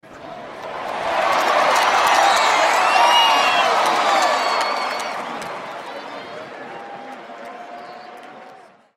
Crowd Cheering Sound ringtone free download
Sound Effects